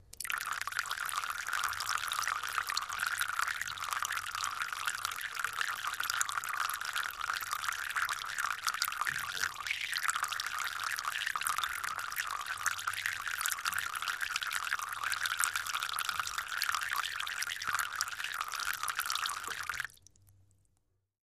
Water Trickle